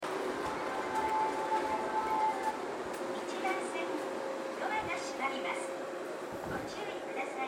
この駅の発車メロディーは両番線ともにテイチク製の「秋桜」が使用されています。
またスピーカーは小ボスが使用されており音質はとてもいいと思います。
発車メロディー途中切りです。